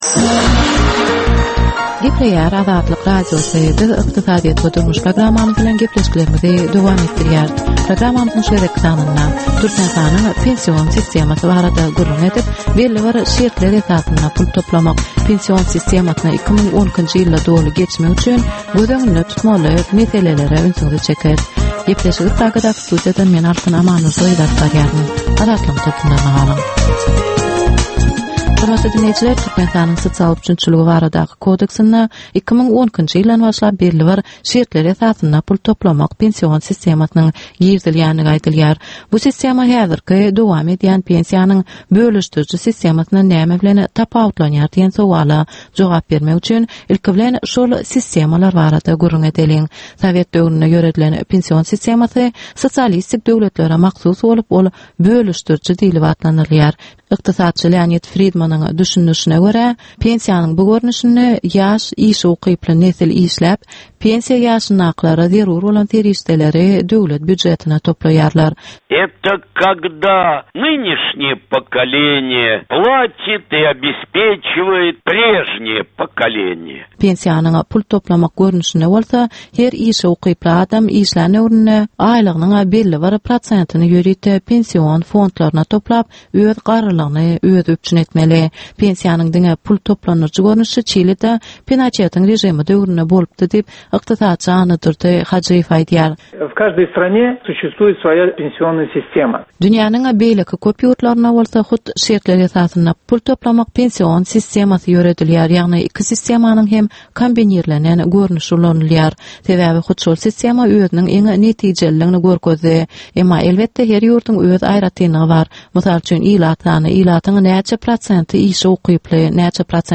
Türkmenistanyň ykdysadyýeti bilen baglanyşykly möhüm meselelere bagyşlanylyp taýýarlanylýan 10 minutlyk ýörite gepleşik. Bu gepleşikde Türkmenistanyň ykdysadyýeti bilen baglanyşykly, şeýle hem daşary ýurtlaryň tejribeleri bilen baglanyşykly derwaýys meseleler boýnça dürli maglumatlar, synlar, adaty dinleýjileriň, synçylaryň we bilermenleriň pikirleri, teklipleri berilýär.